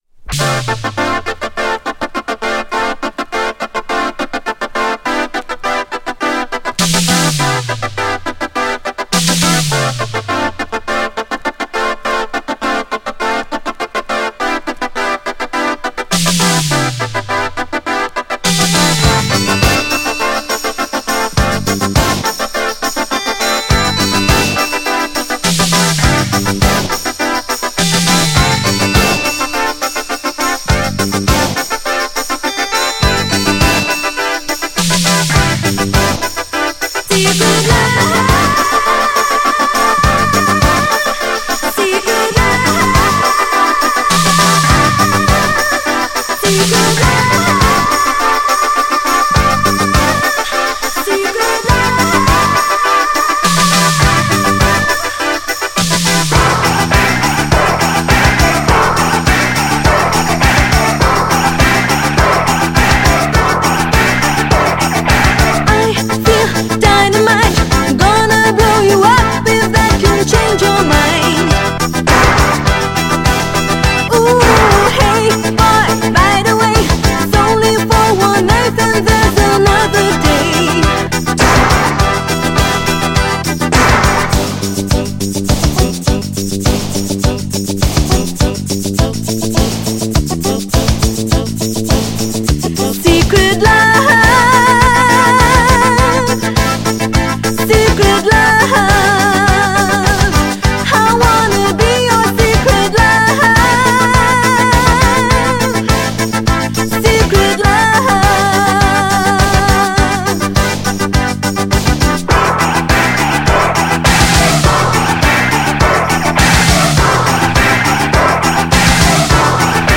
ベルギー発のキュートなユーロDISCOシンガーの初期ヒット
GENRE Dance Classic
BPM 126〜130BPM